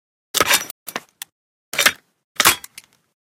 m4a1_grenload.ogg